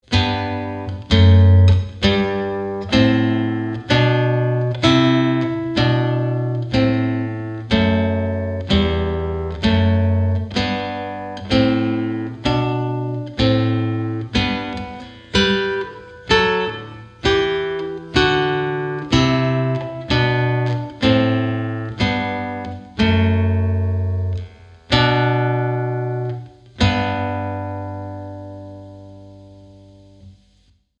Chitarra sola 52